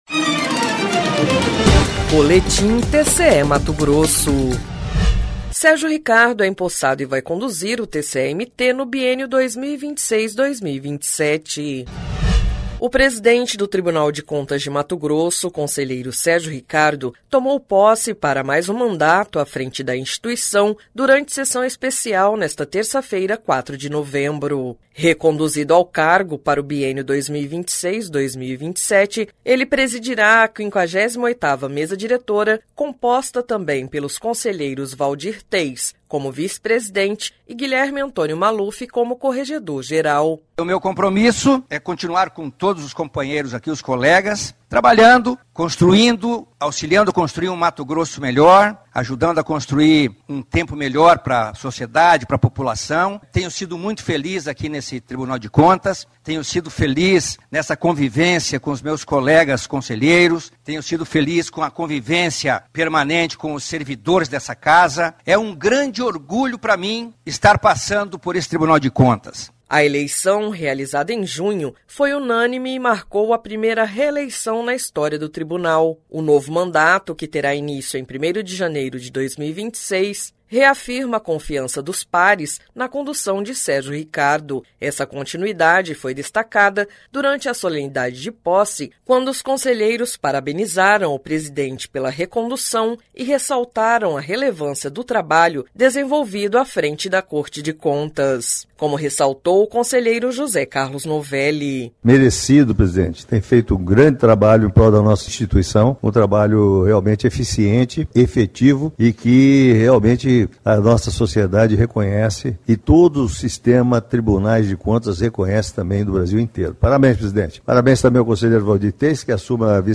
Sonora: Sérgio Ricardo – conselheiro-presidente do TCE-MT
Sonora: José Carlos Novelli – conselheiro do TCE-MT
Sonora: Alisson Carvalho de Alencar – procurador-geral do Ministério Público de Contas